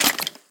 Minecraft Version Minecraft Version 1.21.4 Latest Release | Latest Snapshot 1.21.4 / assets / minecraft / sounds / mob / skeleton / step1.ogg Compare With Compare With Latest Release | Latest Snapshot
step1.ogg